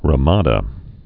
(rə-mädə)